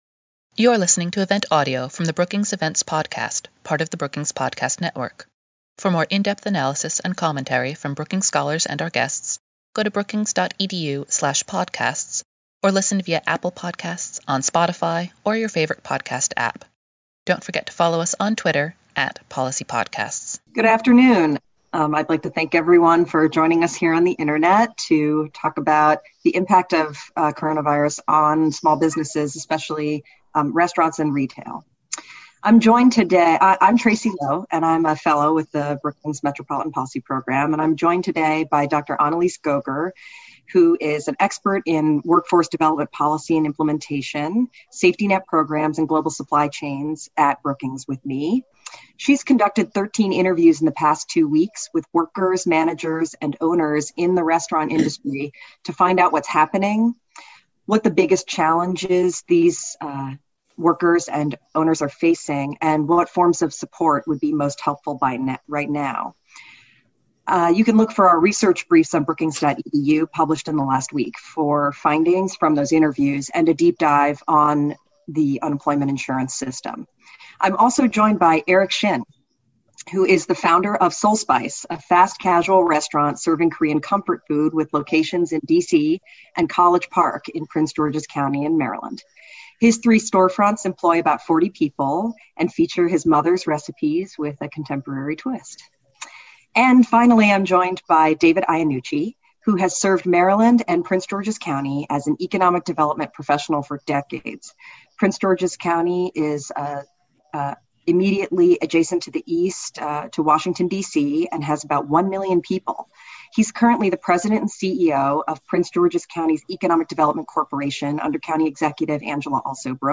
On Thursday, April 9, the Metropolitan Policy Program at Brookings hosted a webinar examining COVID-19’s impacts on the retail and hospitality industries, and highlight creative interventions cities and states are deploying to help small businesses and workers, including unemployment insurance, sick